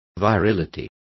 Complete with pronunciation of the translation of virility.